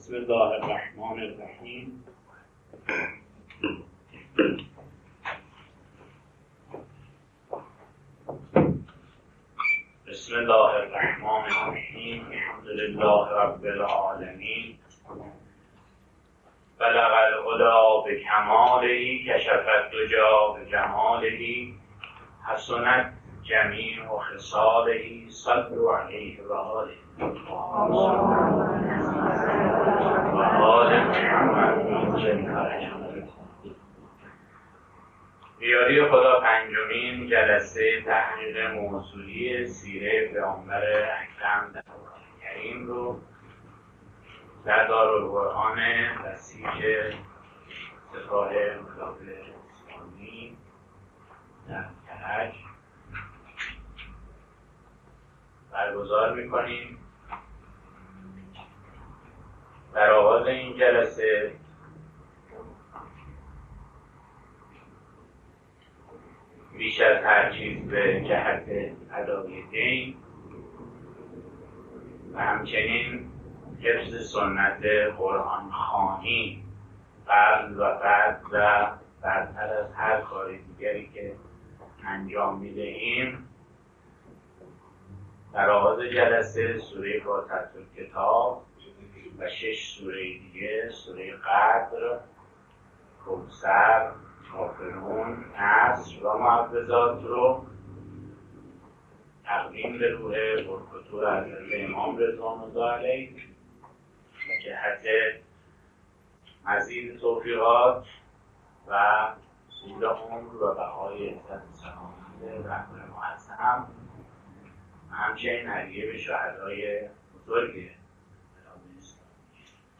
ششمین کارگاه آموزشی پژوهشی مطالعات قرآنی در سیره نبوی